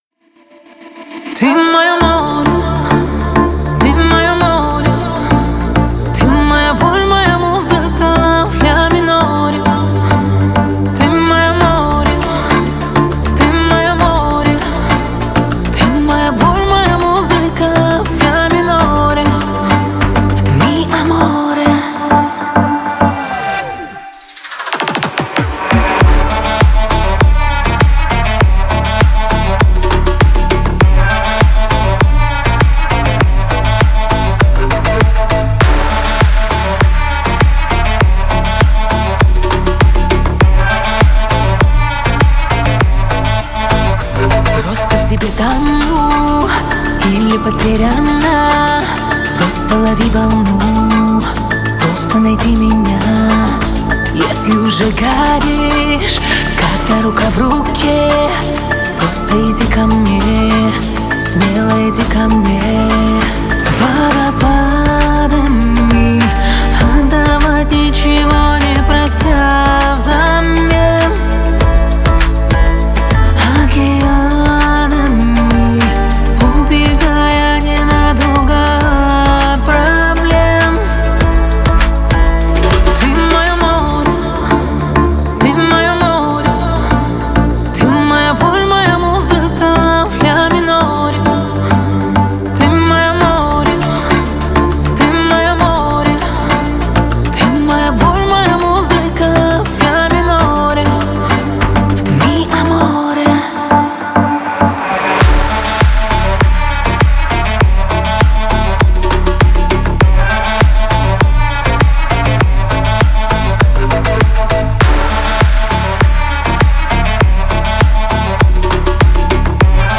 это трек в жанре поп с элементами романтической баллады